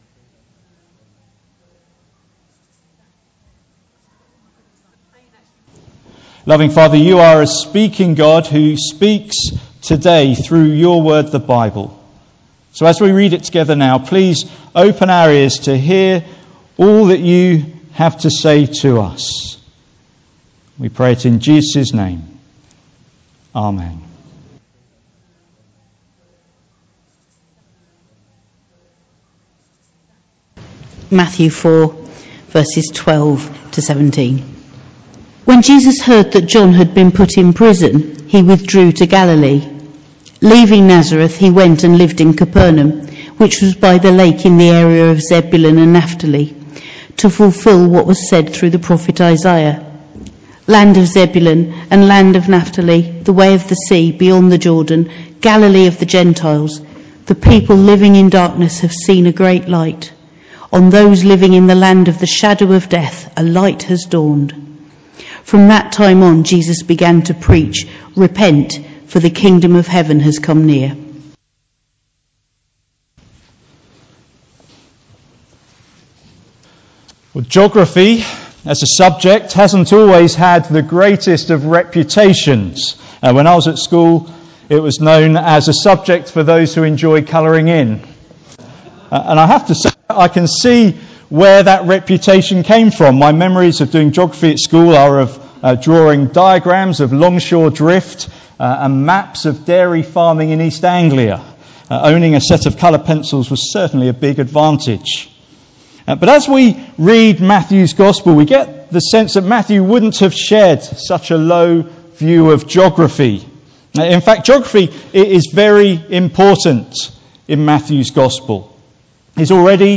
Passage: Matthew 4:12-17 Service Type: Sunday Morning